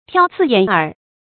注音： ㄊㄧㄠ ㄗㄧˋ ㄧㄢˇ ㄦˊ